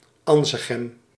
Anzegem (Dutch pronunciation: [ˈɑnzəɣɛm]
Nl-Anzegem.ogg.mp3